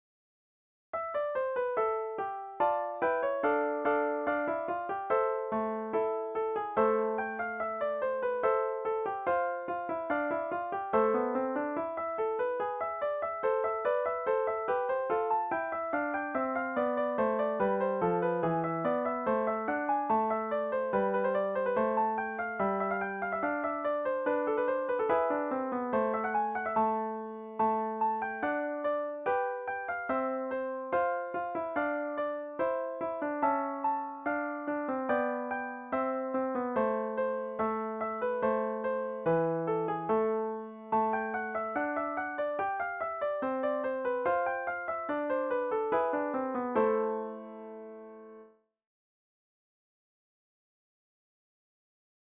arranged for four guitars